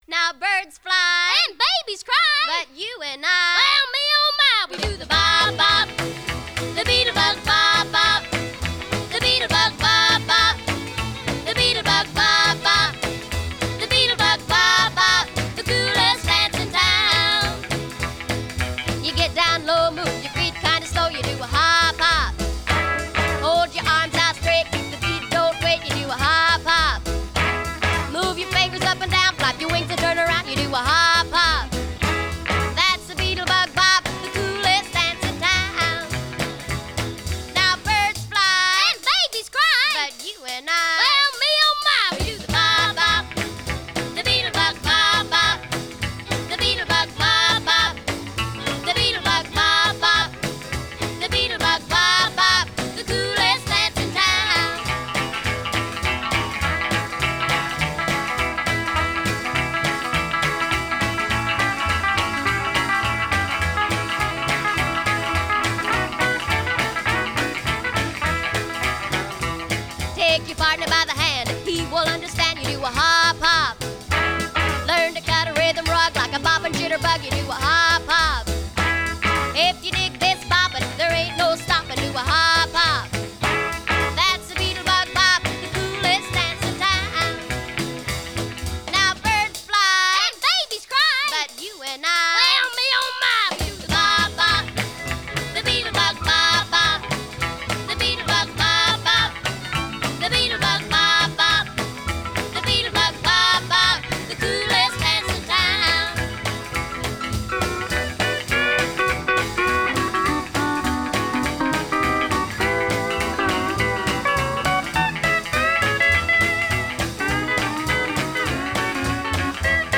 Rock 'N Roll duo
Complete Rock 'N Roll.